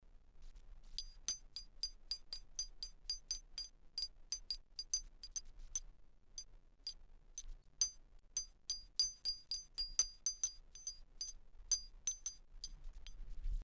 Tags: Bells Glocken Metallic idiophones Music archaeology Musicarchaeology Musikarchäologie Original sound Originals Ovilava Psychoacoustics Psychology of music Roman period Römische kaiserzeit Sound Wels
Sound recordings of original bells from Ovila, Roman Wels (AT